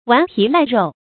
頑皮賴肉 注音： ㄨㄢˊ ㄆㄧˊ ㄌㄞˋ ㄖㄡˋ 讀音讀法： 意思解釋： 見「頑皮賴骨」。